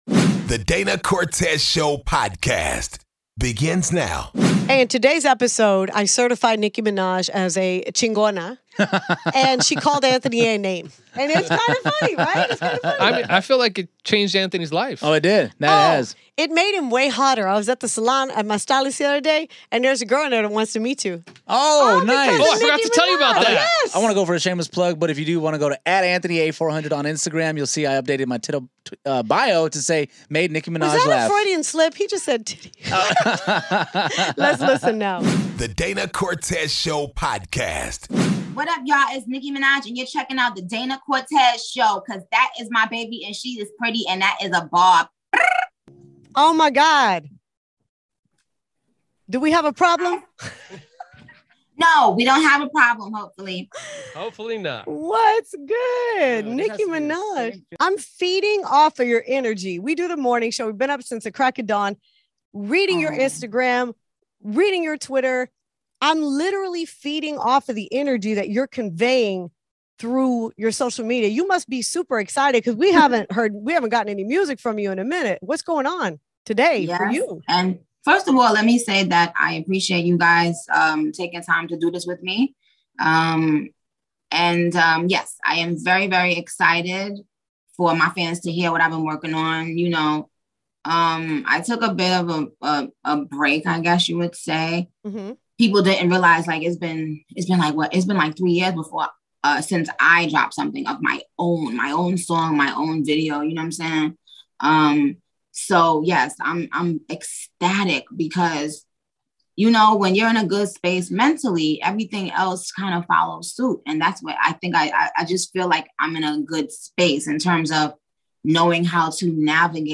DCS Interviews Nicki Minaj
This is the interview that started it all.